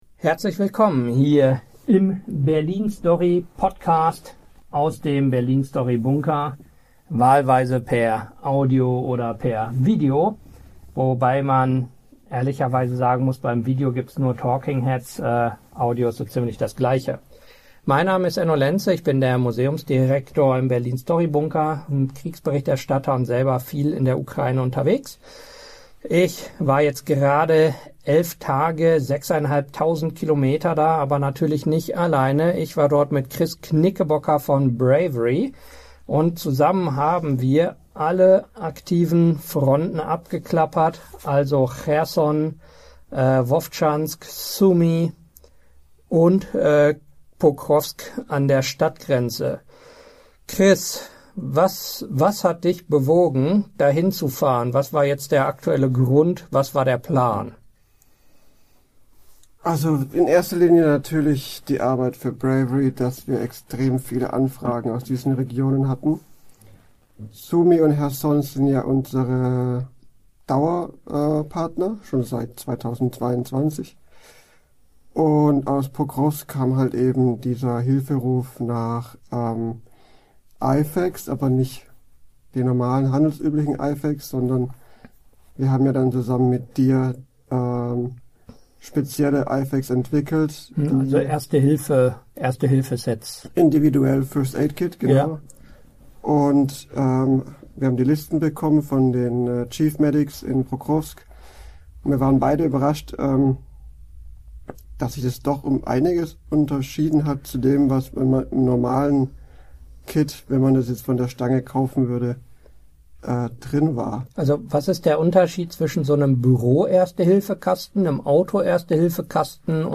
Wir waren in den vergangenen Tagen bei Pokrowsk, Kherson, Sumy und Vovchansk und haben den Großangriff auf Kyiv miterlebt. Hier unser Gespräch dazu.